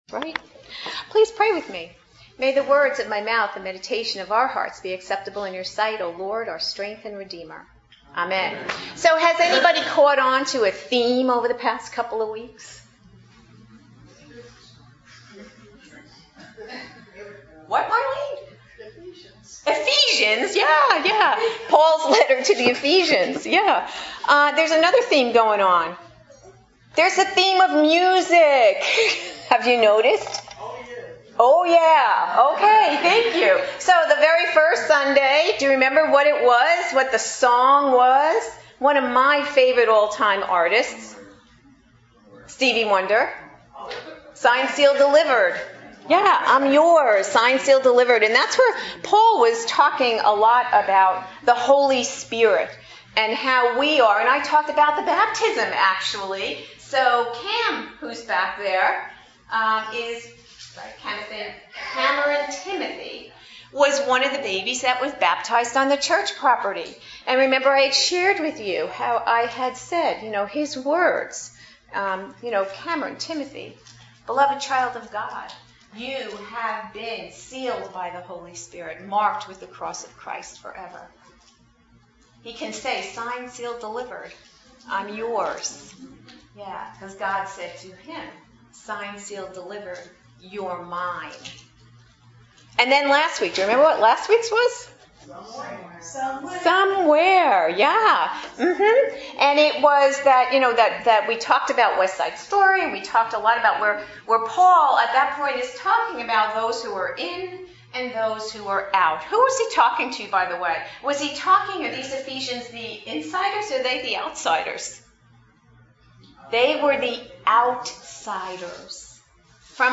Living Faith Church